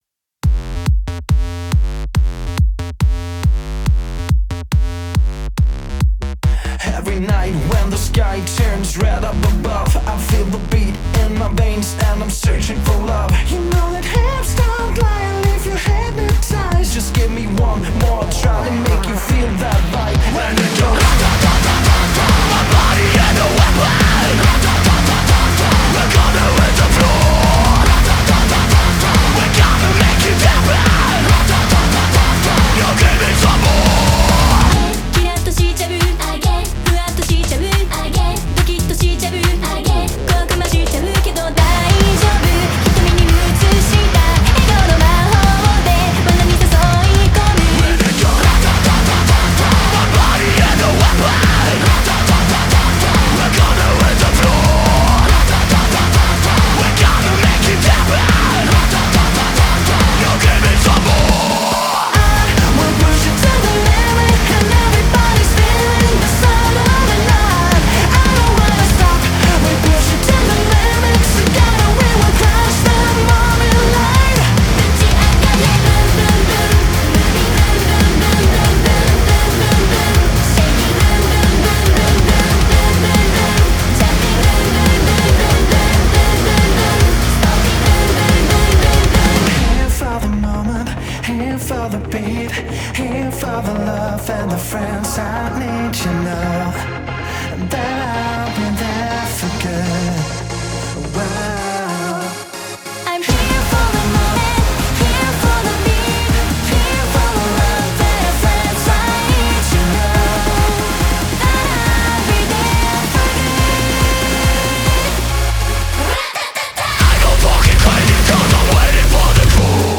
BPM140
Audio QualityPerfect (High Quality)
- Your song must contain both male and female vocals.